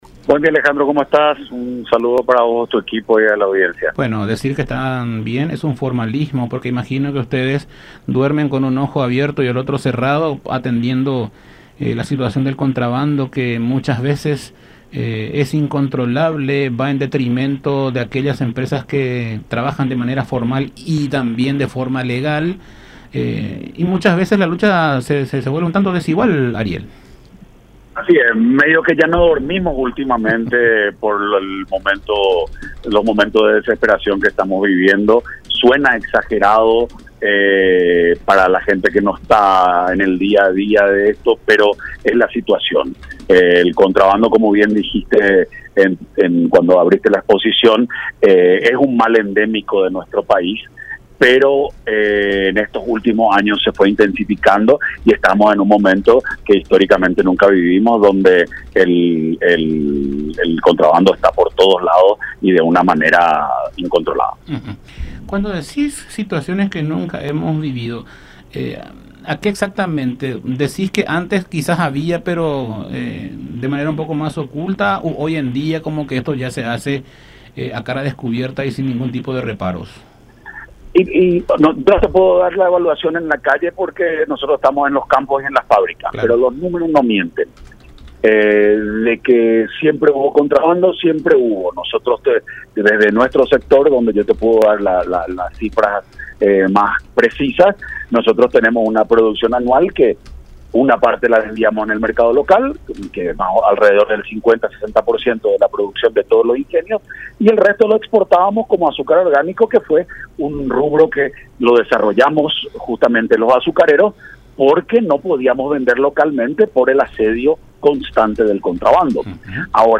en conversación con Enfoque 800 por La Unión